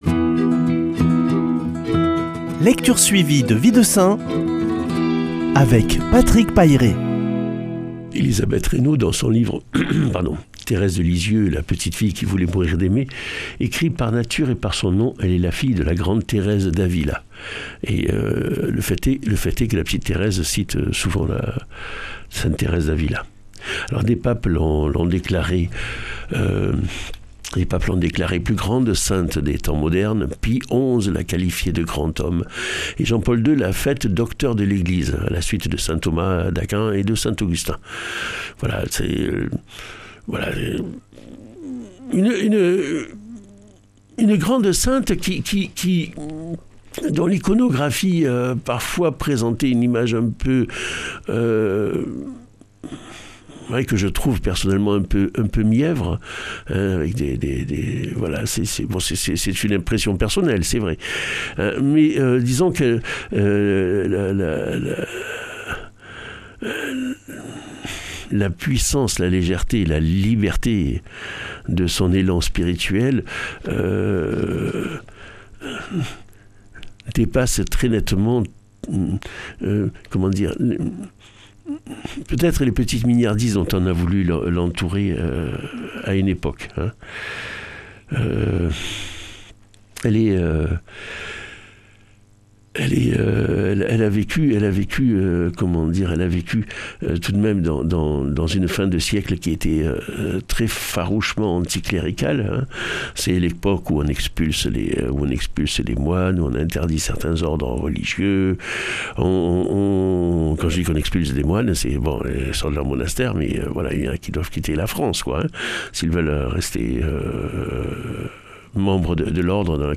Lecture suivie de la vie des saints